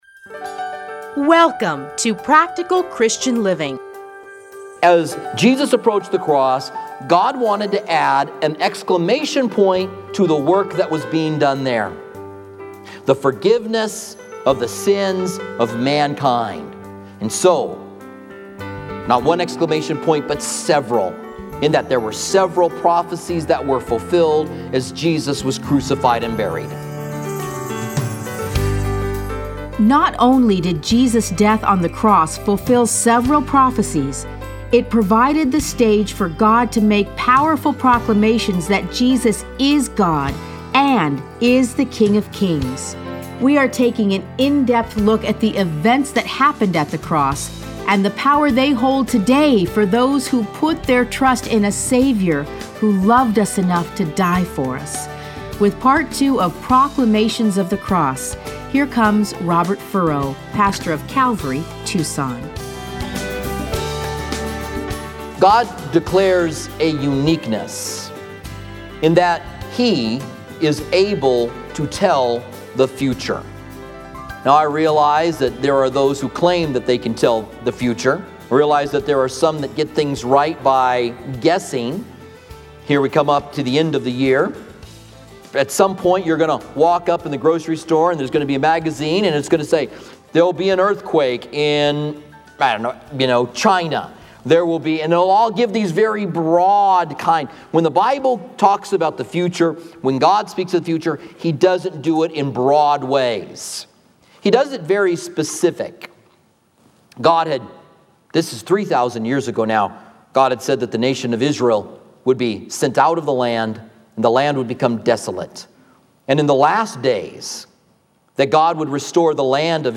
Listen to a teaching from Mark 15:25-39.